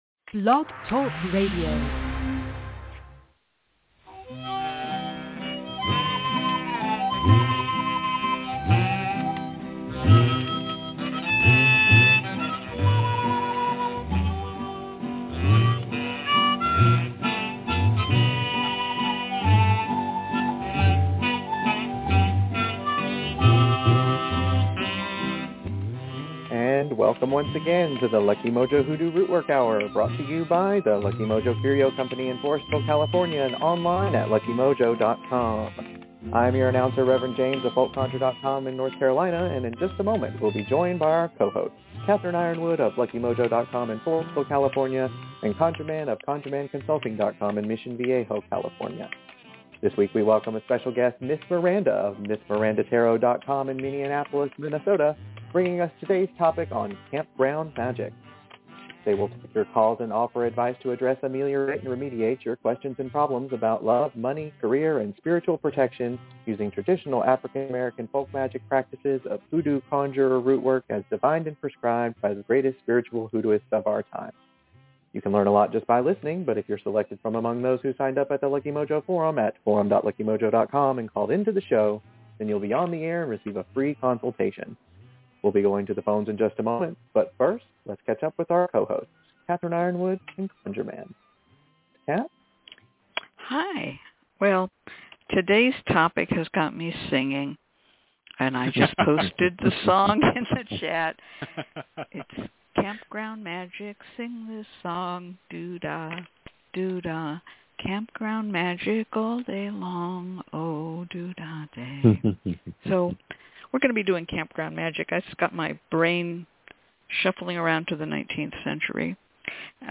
Campground Magic - Lucky Mojo Radio Show 6/16/24